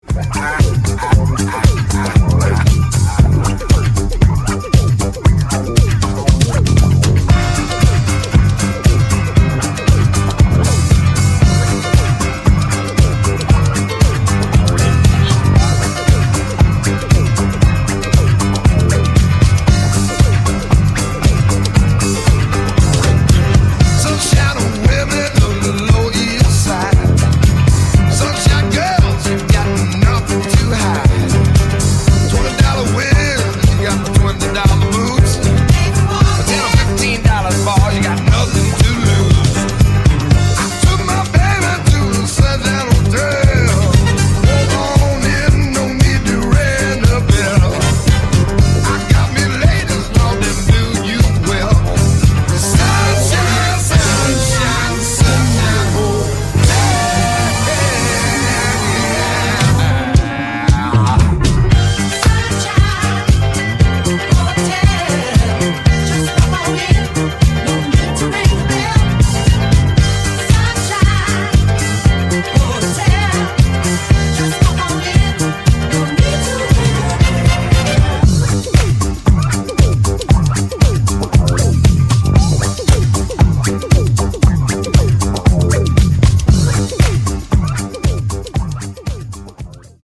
賑わい感を演出するご機嫌なナンバーで最高です。